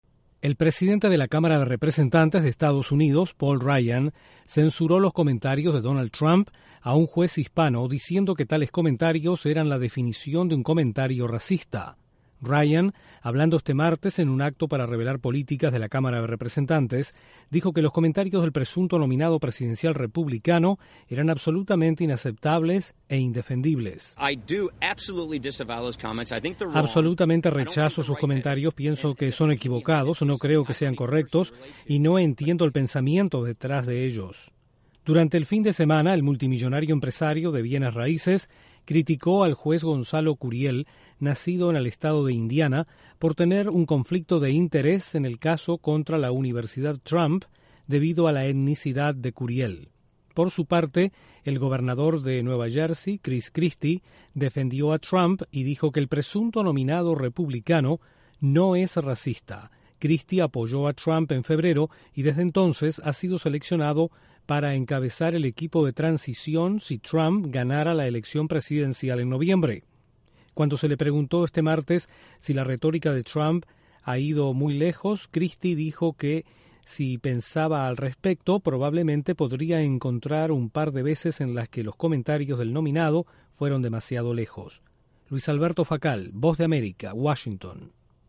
El republicano más influyente en el Congreso de Estados Unidos critica a Donald Trump por comentarios sobre un juez hispano. Desde la Voz de América en Washington informa